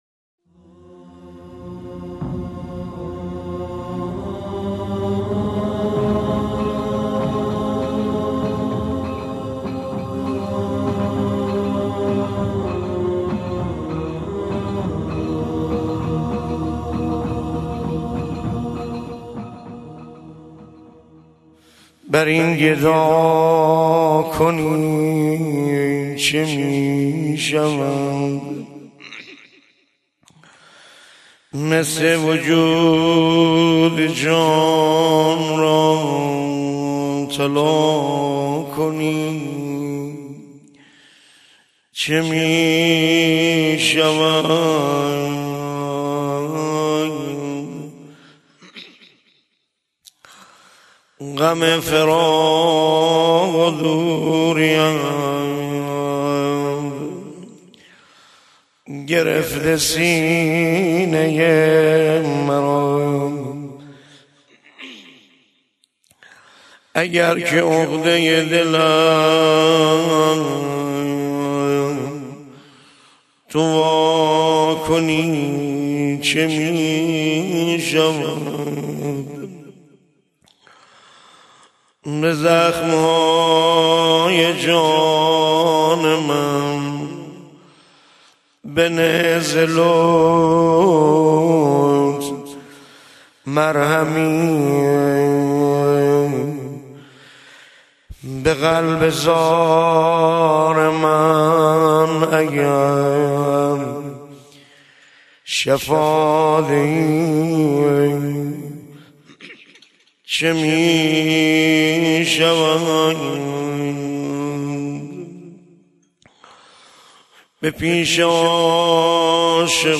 مراسم ایام عاشورا ۱۴۳۷ هجری قمری روز پنجم